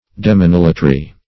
Demonolatry \De`mon*ol"a*try\, n. [Gr. dai`mwn demon + latrei`a